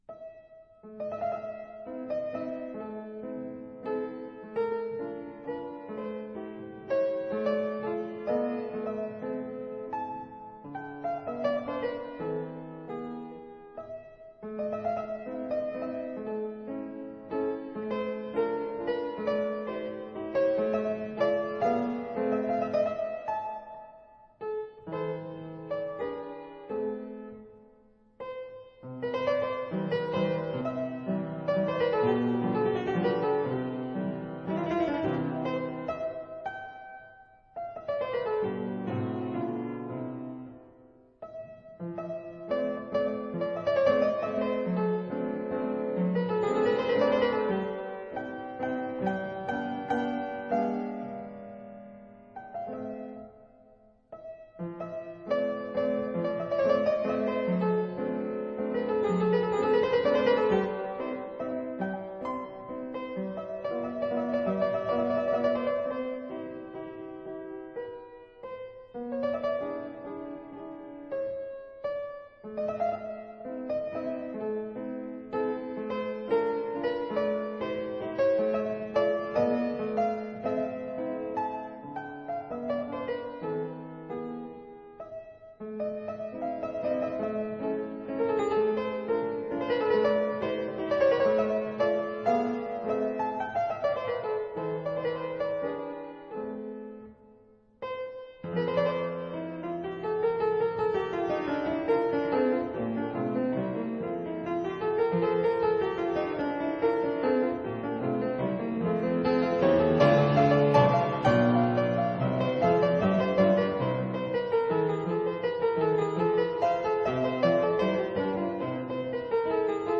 四手聯彈，如影隨形
這是在古鋼琴上（Pianoforte）演奏的四手聯彈。
四手聯彈在鋼琴上的變化，非常豐富。
第二雙手則是如影隨形，或重複、或疊聲、或陪伴哼唱。